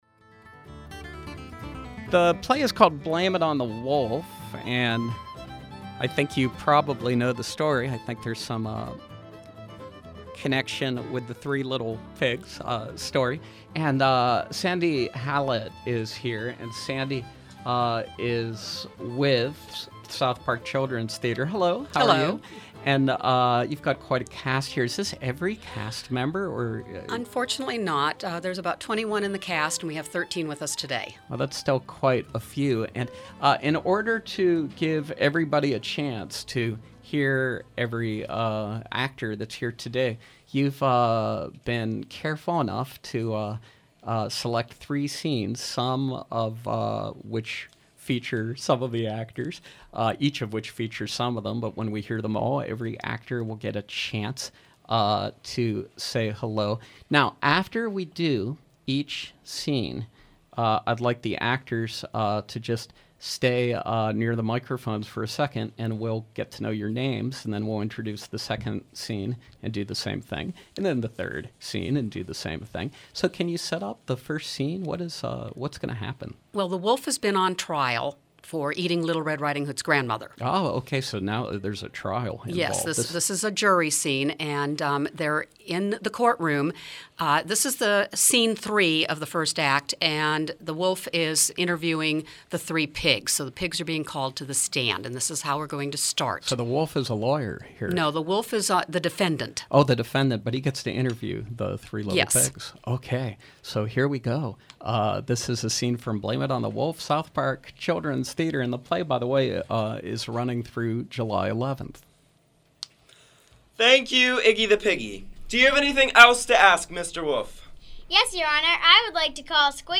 The youth cast of South Park Theatre’s latest production, ‘Blame It on the Wolf’, perform selected scenes from the play.